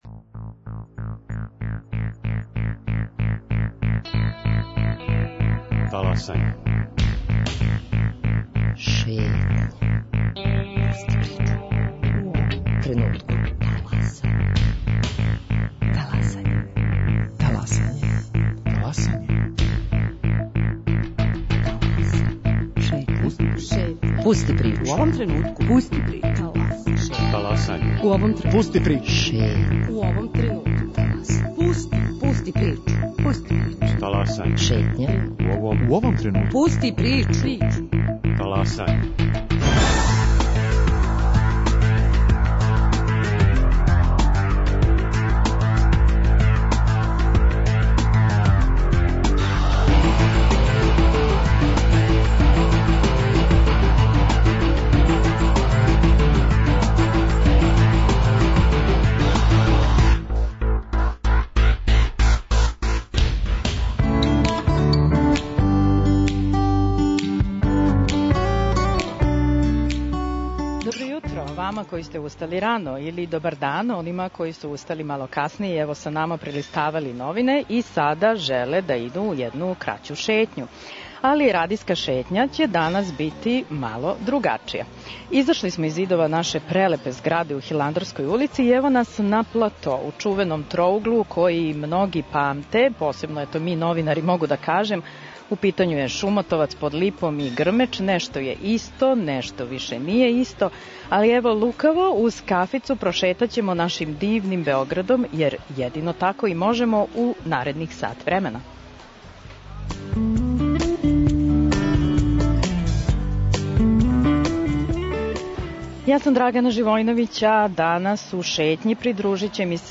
Придружите им се у уторак 14. јуна у 10 часова на платоу испред Радио Београда.